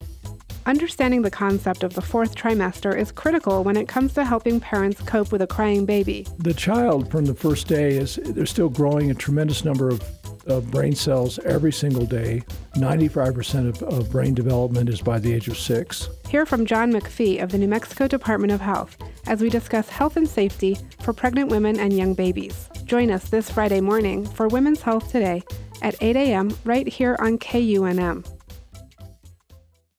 Listen to the promo here: